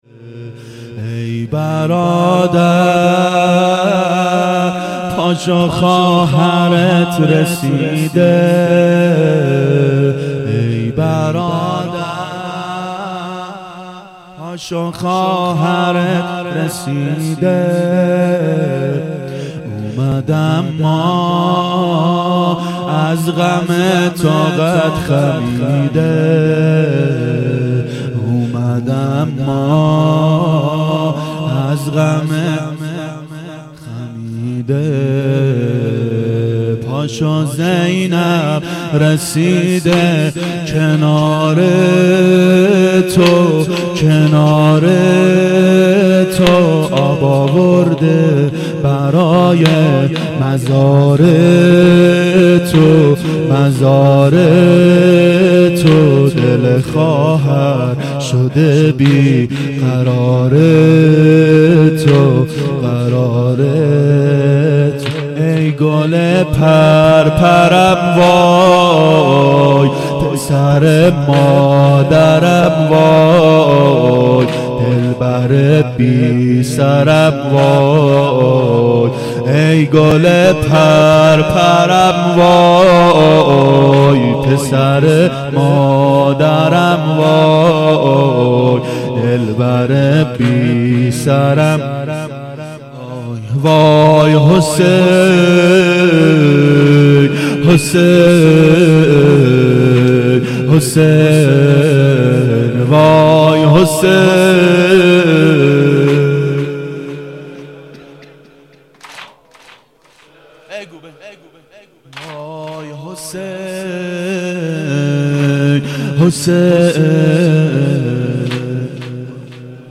ظهر اربعین 1391 هیئت شیفتگان حضرت رقیه سلام الله علیها